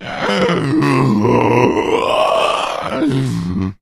fracture_attack_5.ogg